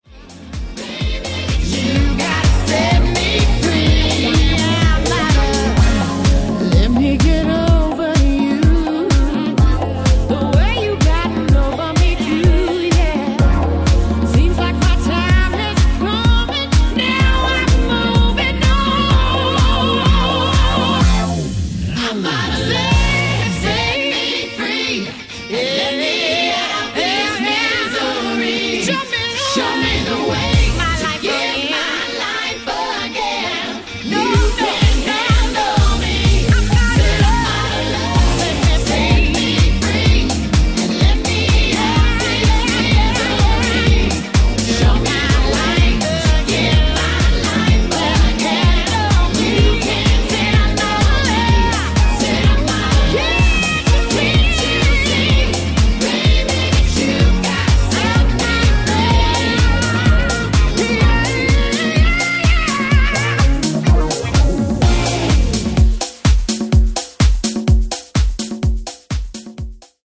NU-Disco Remix